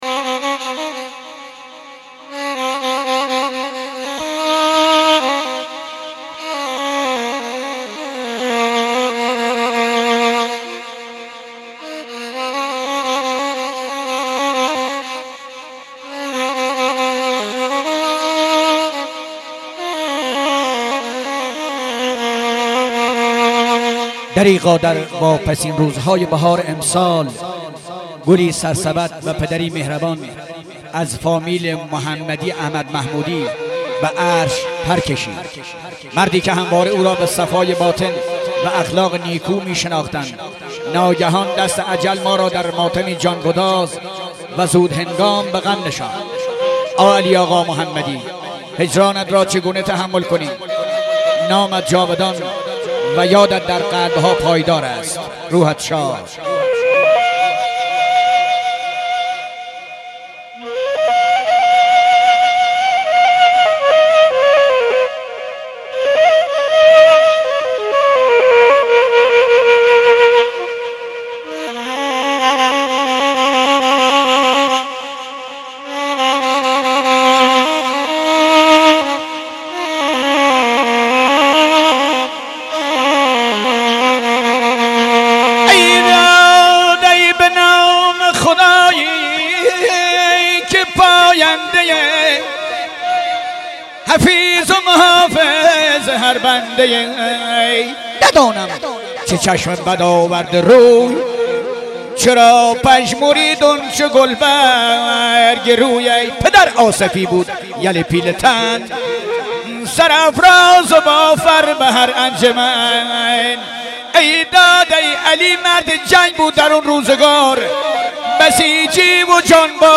دانلود عزاداری و سوگواری لری بختیاری
همراه با نوای نی
سبک ها: دندال (دوندال)، گاگریو – گویش: بختیاری